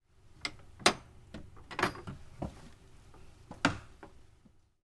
缓慢敲击木板
描述：金属锤敲击木材表面，户外氛围。
标签： 锤子 金属
声道单声道